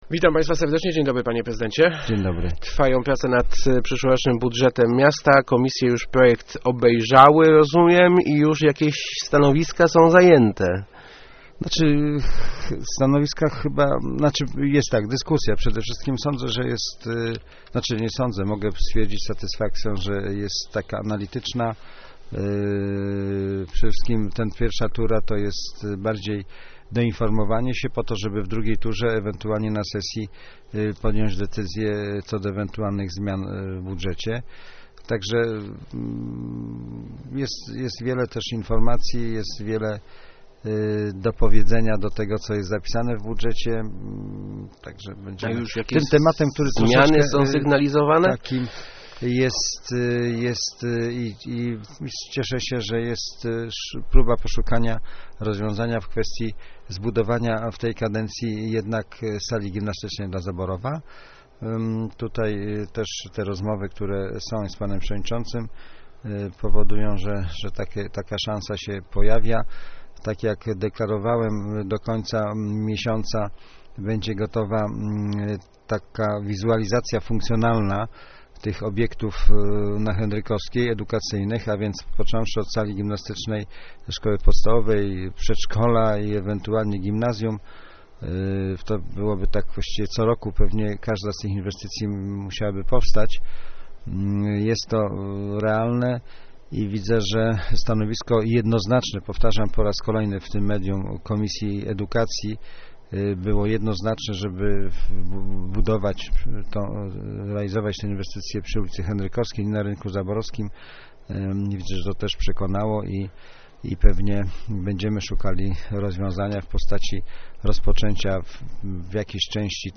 Gościem Kwadransa jest prezydent Tomasz Malepszy ...